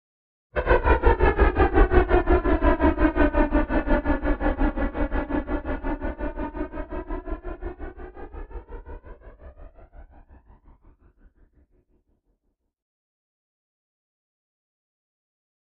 Phasey Descend | Sneak On The Lot
Phasey Descend Helicopter Descend with Phase